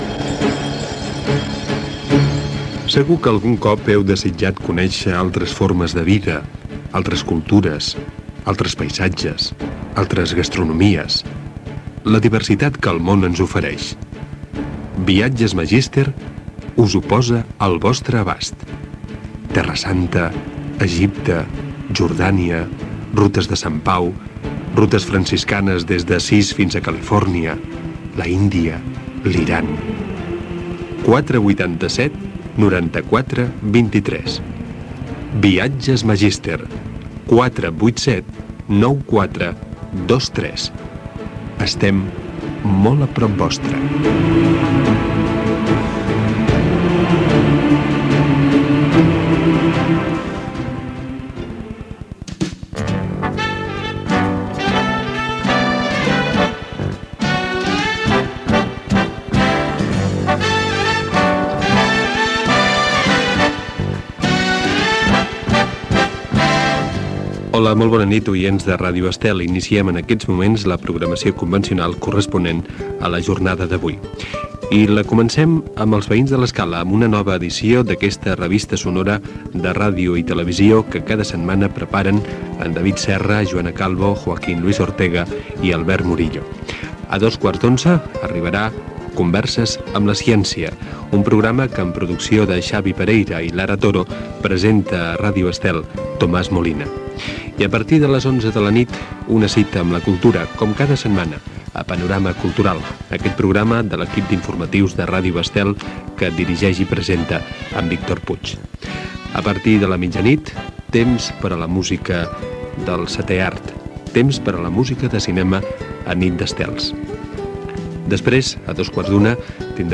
Publicitat, programació de nit, "Els veïns de l'escala" tercera edició: presentació i sumari.
Divulgació
FM